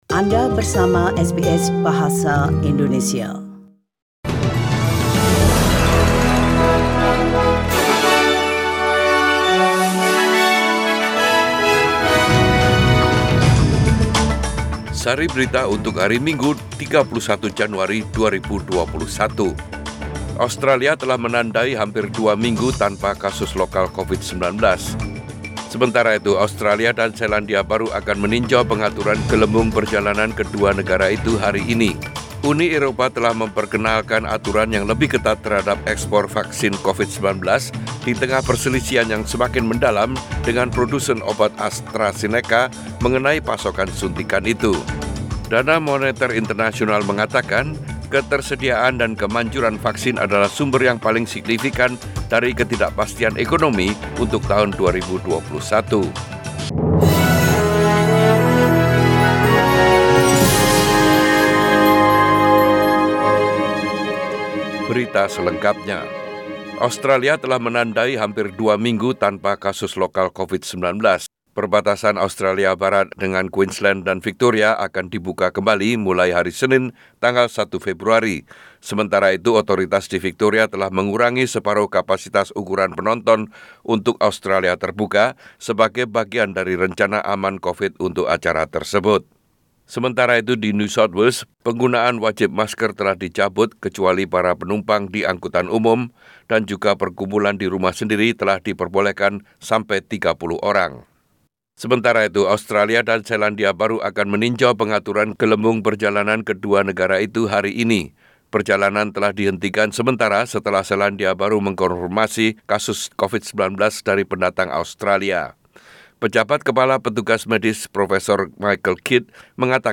SBS Radio News in Bahasa Indonesia - 31 January 2021